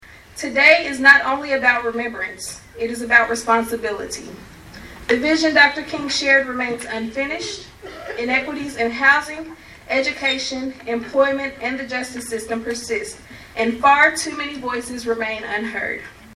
Monday morning, the community gathered at HCC to honor Dr. Martin Luther King Jr.’s vision for justice and equality.